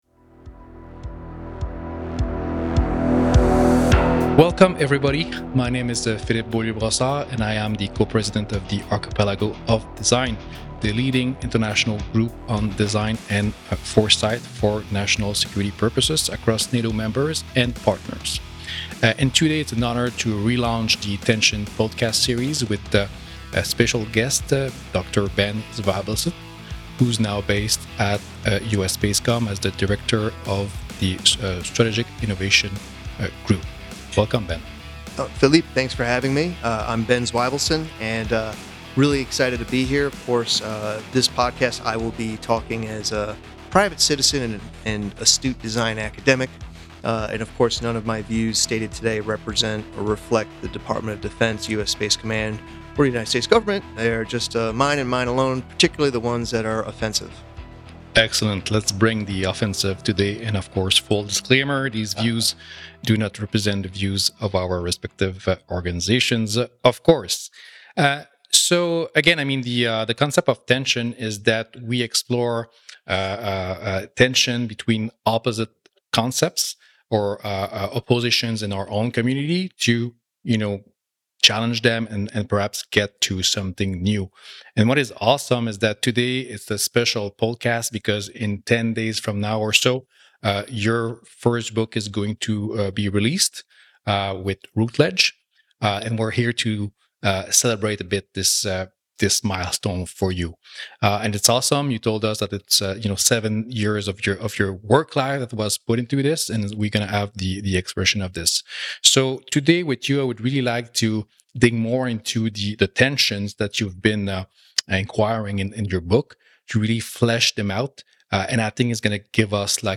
in conversation with guest